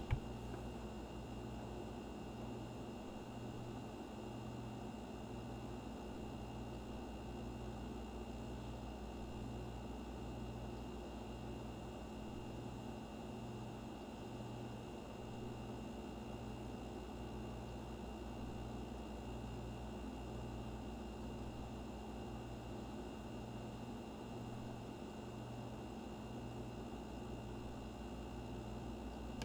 motor.wav